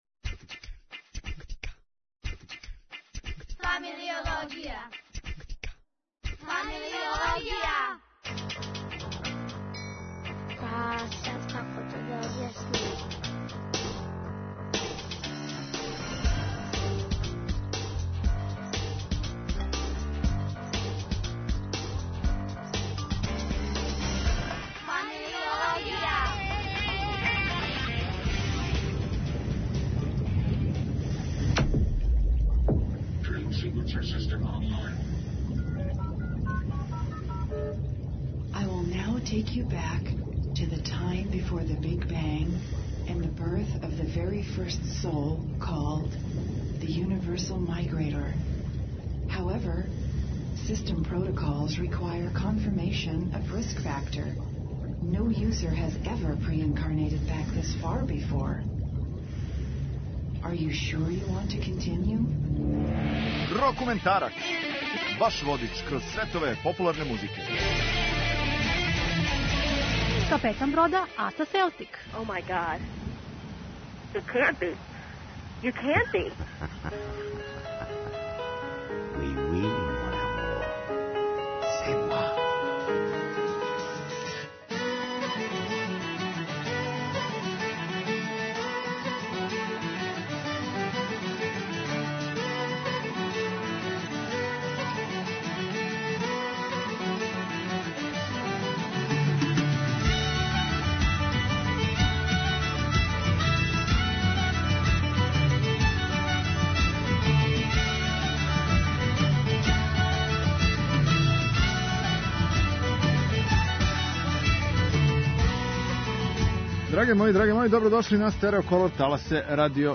прогресивни рок са брит-поп звуком осамдесетих